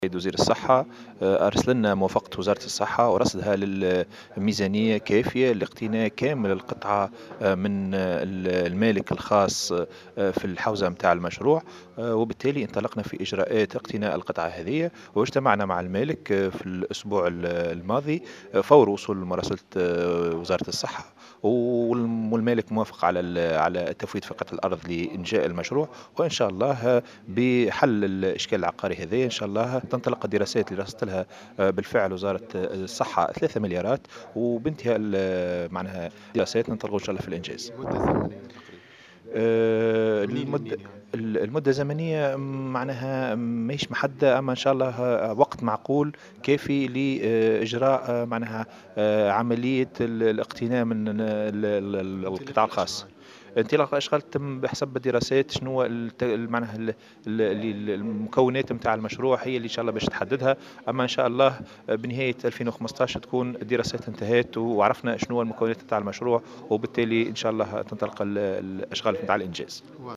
أكد والي القيروان محسن المنصوري اليوم لمراسل "جوهرة أف أم" أنه تم تجاوز الإشكاليات العقارية المتعلقة ببناء مستشفى جامعي بولاية القيروان.